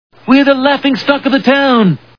The Simpsons [Burns] Cartoon TV Show Sound Bites
burns_laughing_stock2.wav